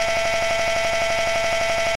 Descarga de Sonidos mp3 Gratis: cd adelantar.
cd-skipping.mp3